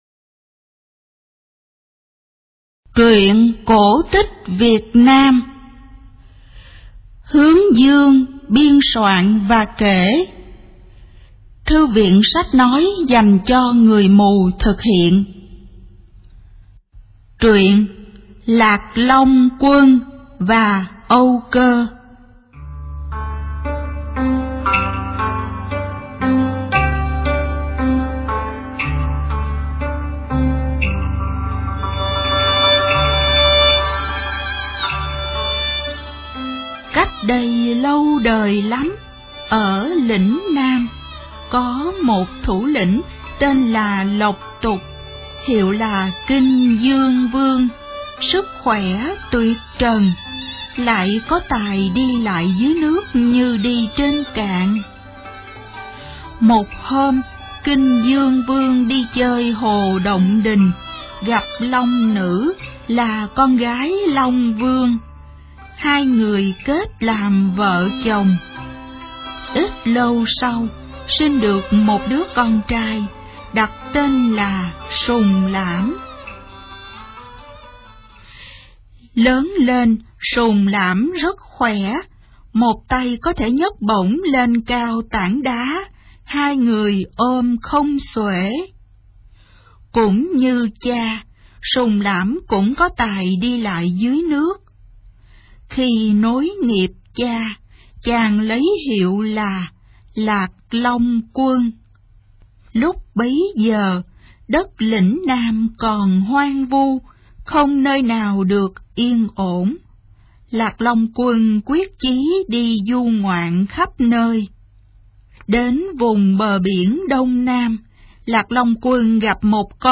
Sách nói | TRUYỆN CỔ TÍCH VIỆT NAM P1